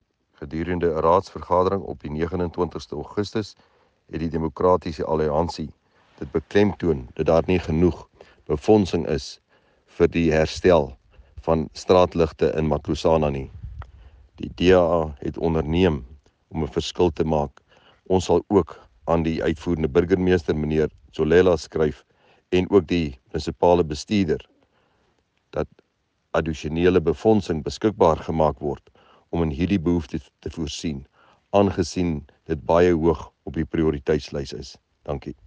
Note to Broadcasters: Please find linked soundbites in
Rdl-Johannes-le-Grange-Straatligte-Afr.mp3